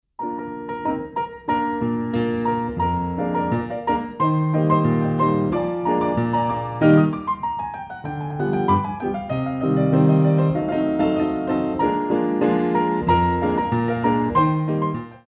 Coda plus retenue pour la gauche